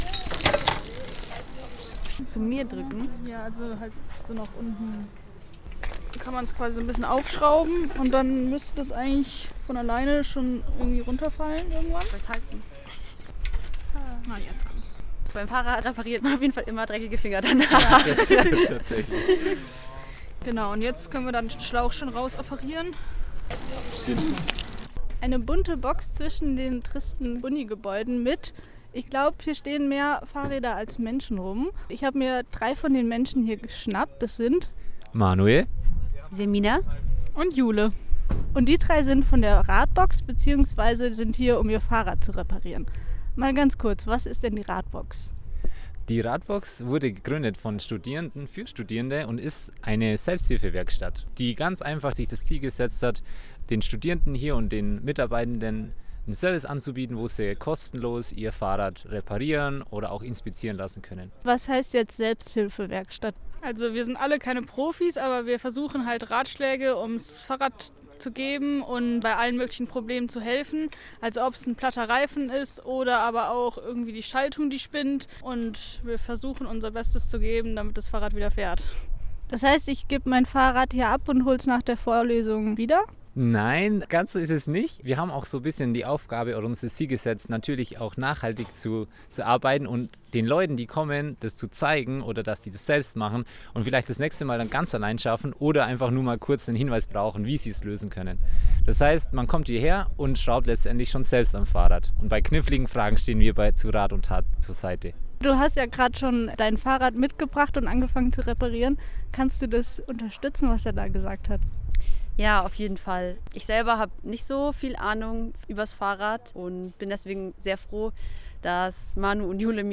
• Schalltwerk-Radiointerview (01.05.2023)